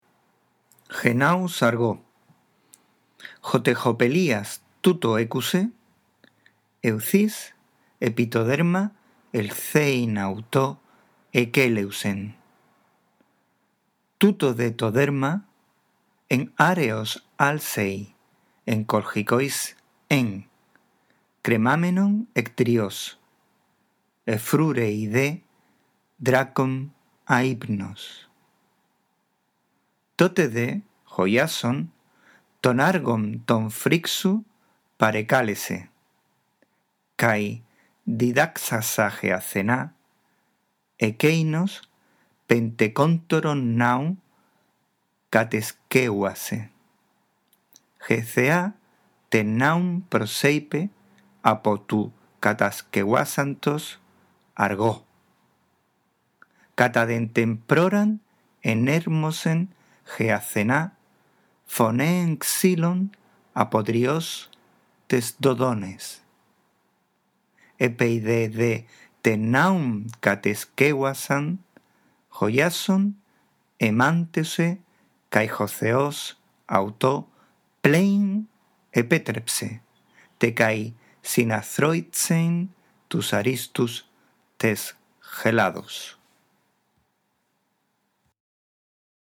2.ª actividad. ἡ ἀνάγνωσις, la lectura
A.1. Lee, en primer lugar, despacio y en voz alta el texto. No te olvides de respetar los signos de puntuación.